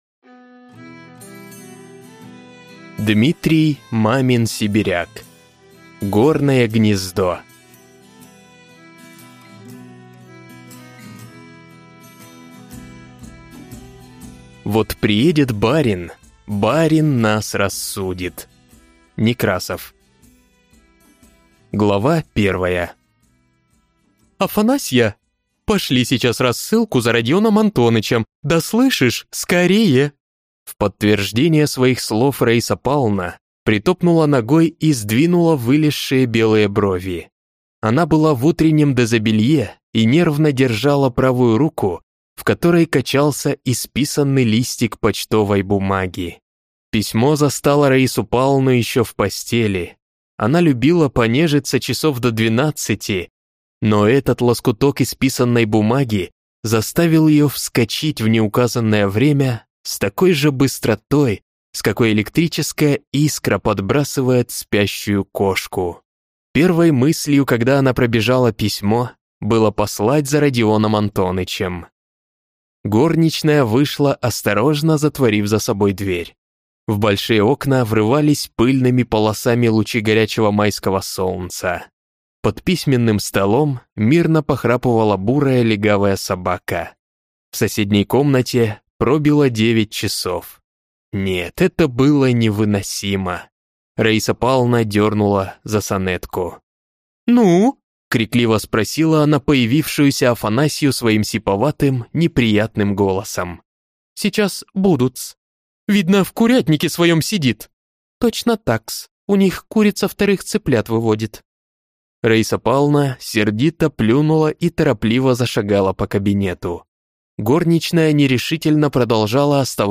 Аудиокнига Горное гнездо | Библиотека аудиокниг